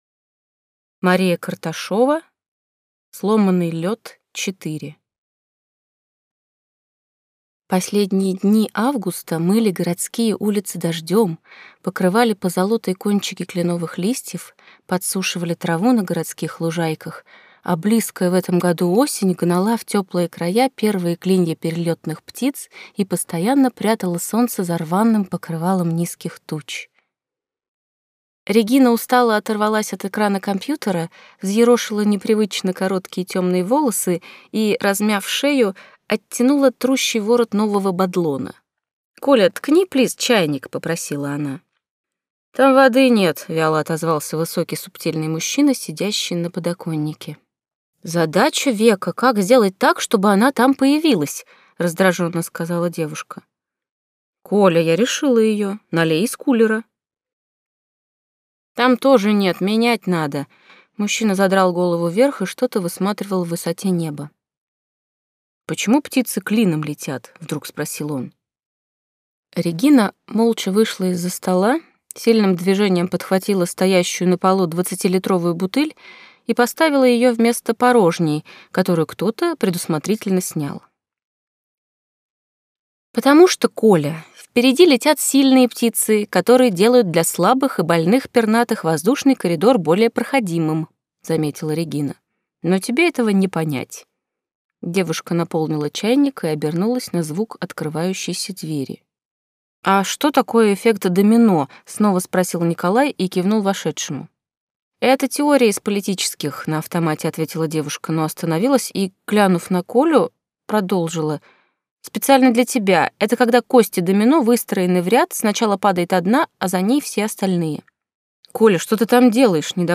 Аудиокнига Сломанный лёд-4 | Библиотека аудиокниг